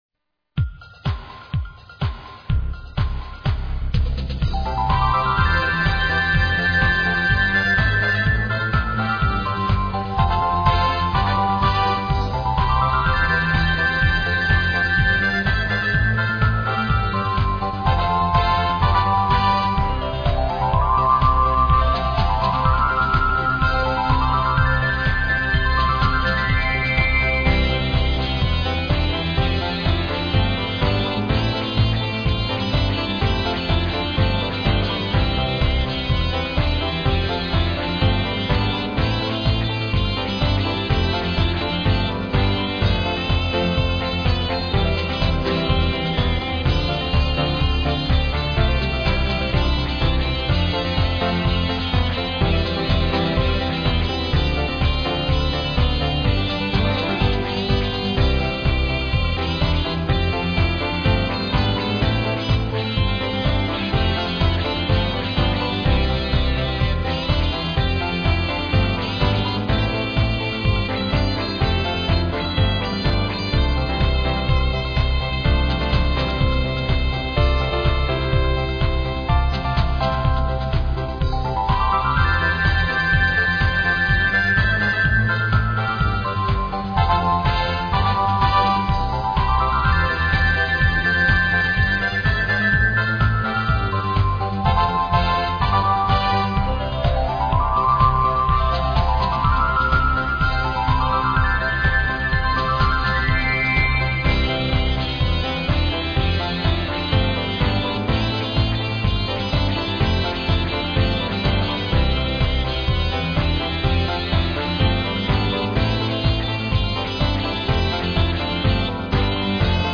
アンサンブル曲